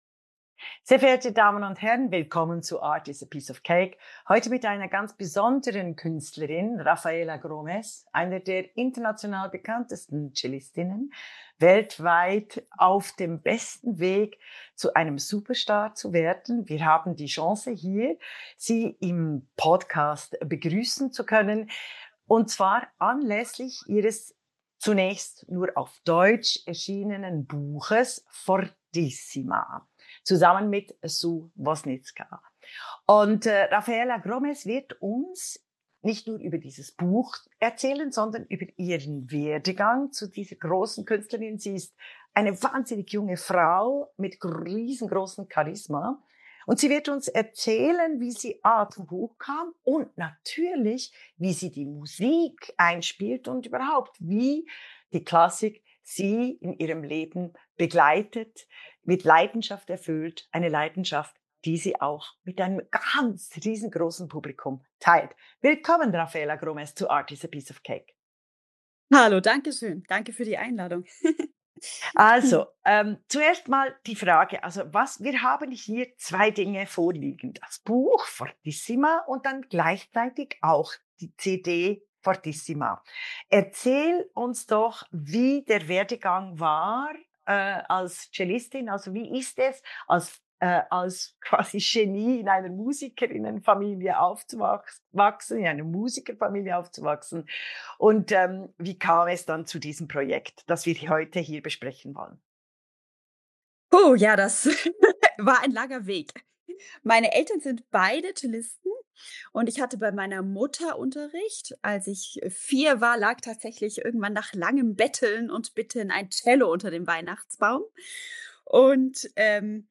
Die poetische Rückkehr vergessener Komponistinnen: "Fortissima." Ein Gespräch zwischen dem internationalen Star der Klassik